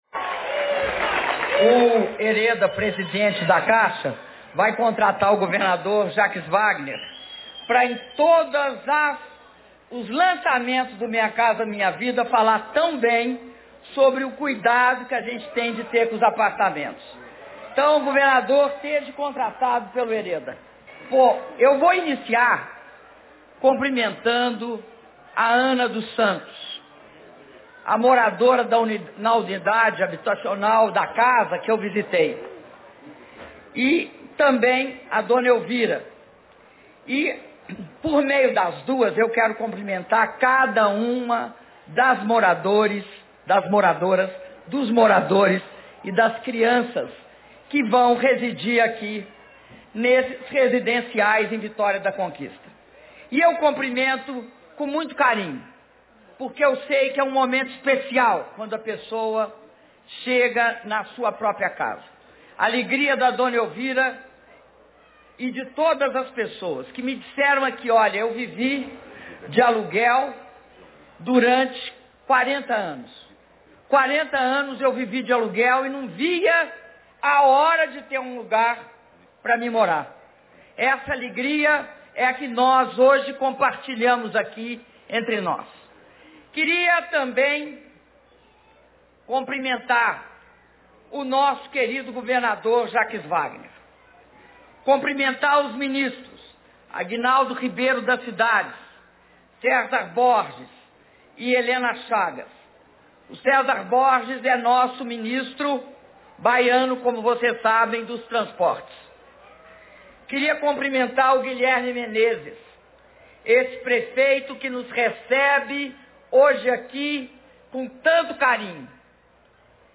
Áudio do discurso da Presidenta da República, Dilma Rousseff, na cerimônia de entrega de 1.740 unidades habitacionais dos Residenciais Acácia, Ipê, Pau Brasil e Jequibitá, do Programa Minha Casa Minha Vida II - Vitória da Conquista/BA